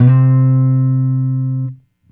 Guitar Slid Octave 01-C2.wav